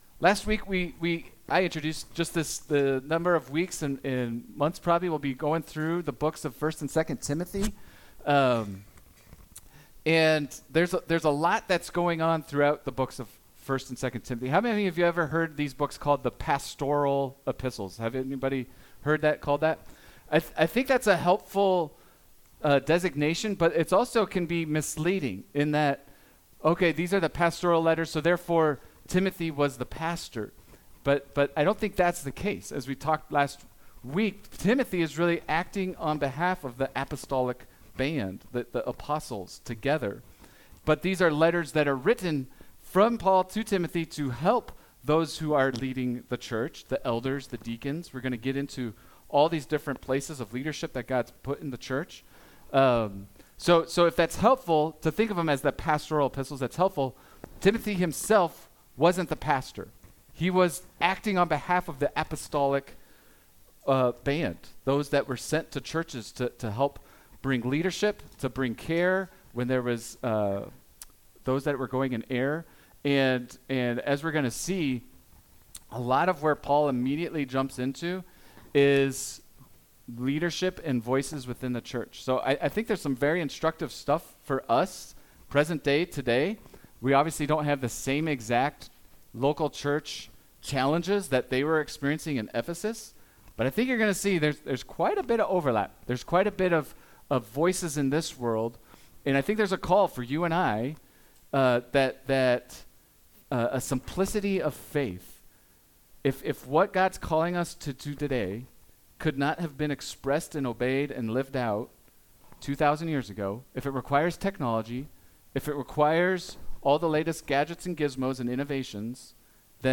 1 & 2 Timothy Service Type: Sunday « The Life of Timothy